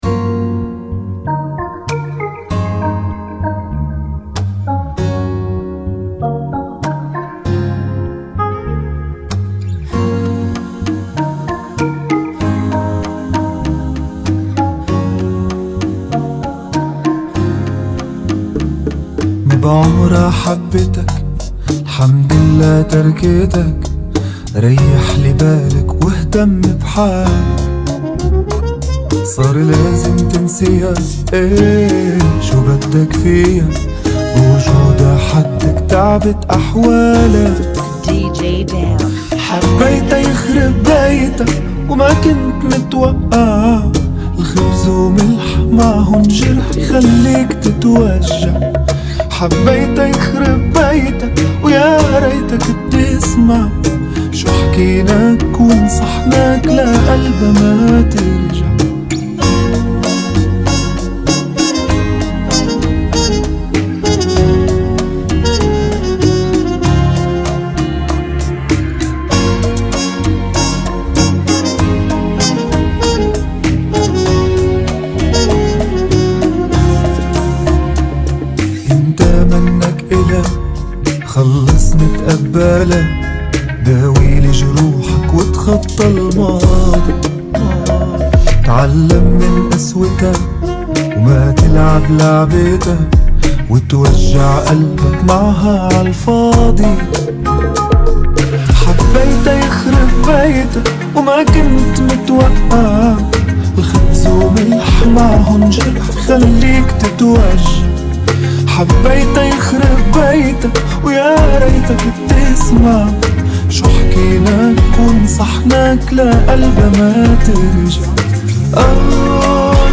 97 BPM
Genre: Bachata Remix